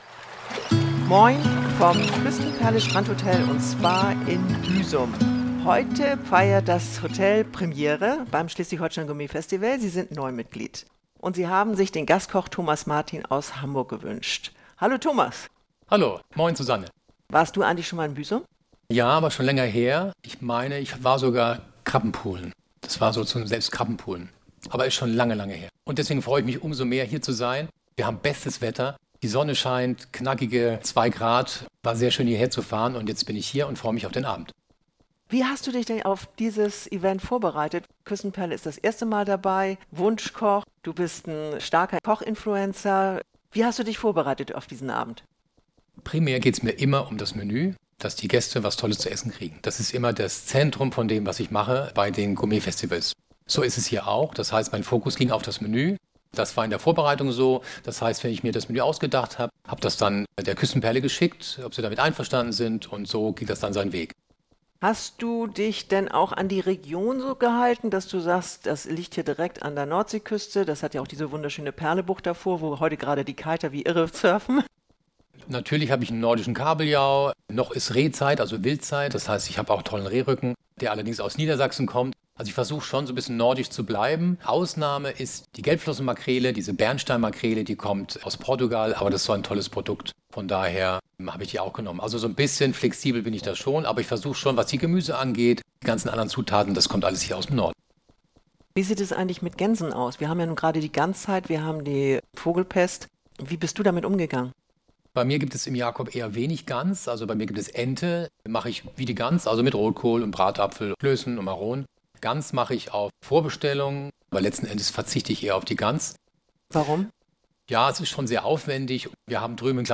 Mehr Gelassenheit, Sicherheit in Formulierungen und Wertschätzung gegenüber jüngeren Köchen und Service hat der vielfach ausgezeichnete Küchenchef vom Restaurant Jacobs in seiner Karriere als TV-Juror von der ZDF-Sendung ‚Die Küchenschlacht‘ gelernt. Warum für den Koch-Influencer Social Media ein wichtiger Bestandteil seiner Arbeit ist und welche Tipps er dem SHGF für die Ausweitung der Gästeklientel gibt, das erfahren Sie im kurzweiligen Gespräch mit Thomas Martin in Folge 91.